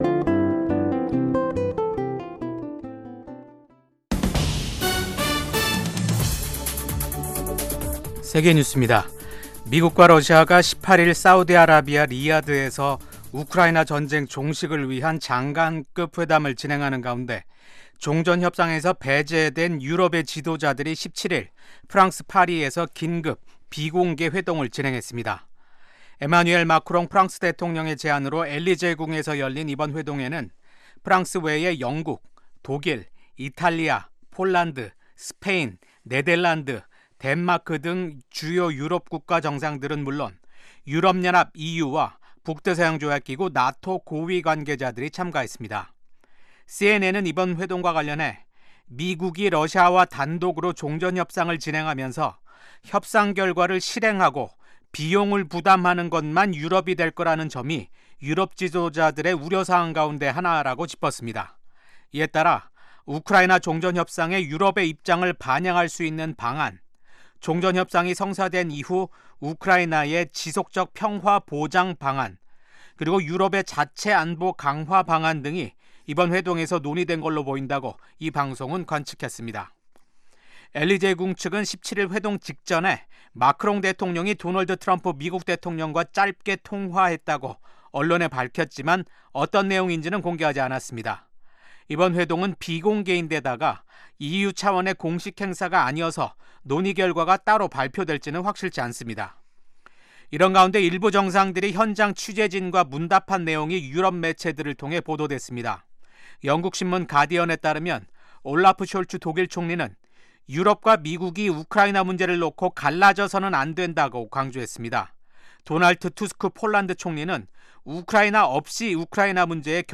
VOA 한국어 방송의 아침 뉴스 프로그램 입니다. 한반도 뉴스와 함께 밤 사이 미국과 세계 곳곳에서 일어난 생생한 소식을 빠르고 정확하게 전해드립니다.